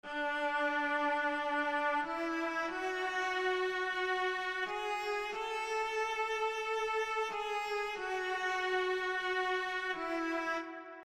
描述：一些真正的大提琴与一个朋友的电子器件的叠加样本。
标签： 大提琴 温柔 忧郁 真正的大提琴
声道立体声